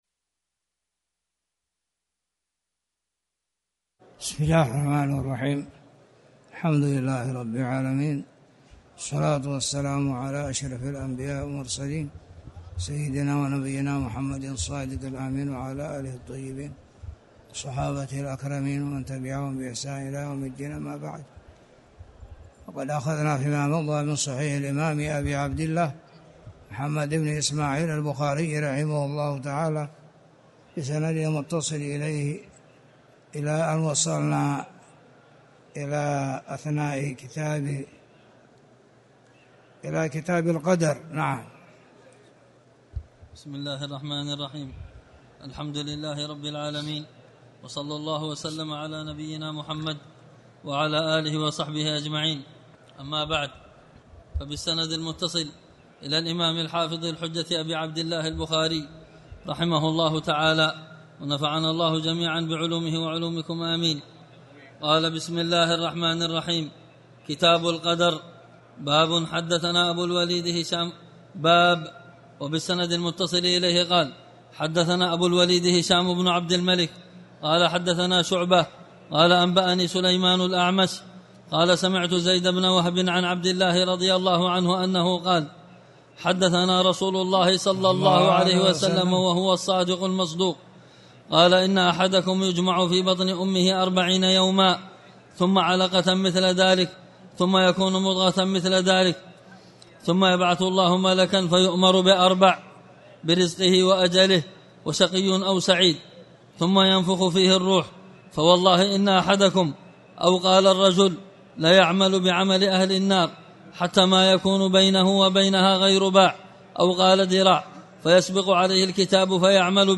تاريخ النشر ١٤ ذو القعدة ١٤٣٨ هـ المكان: المسجد الحرام الشيخ